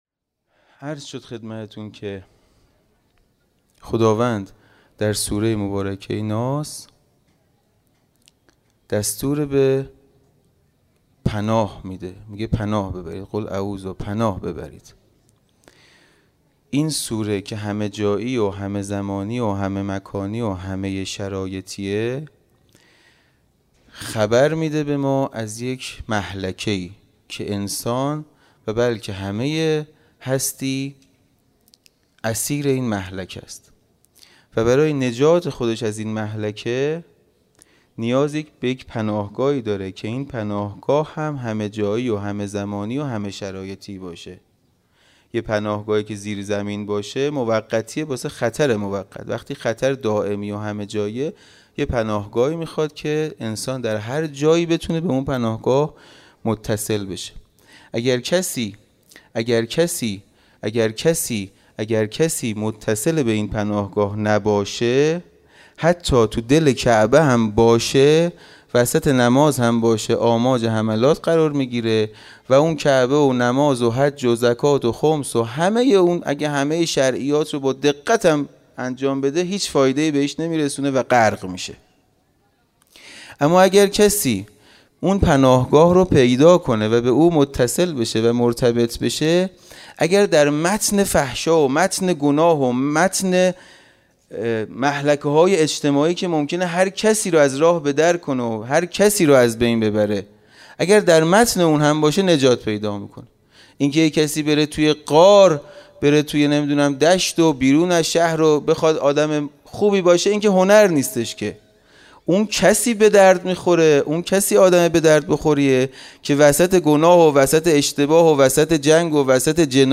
خیمه گاه - حسینیه کربلا - شب هفتم محرم -سخنرانی
حسینیه کربلا